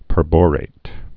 (pər-bôrāt)